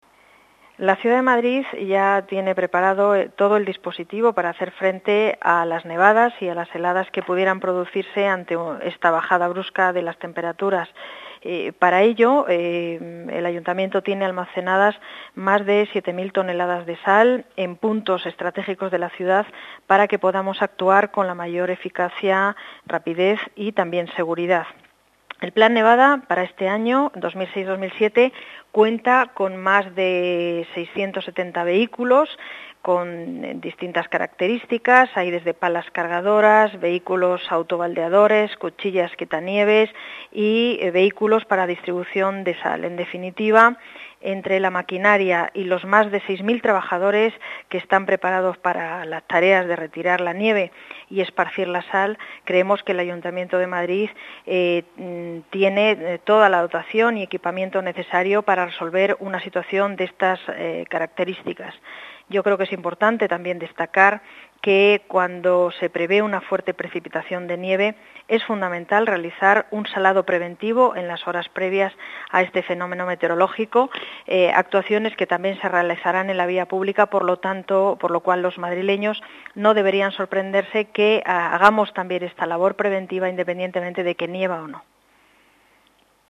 Nueva ventana:Paz González, concejala de Medio Ambiente y Servicios a la Ciudad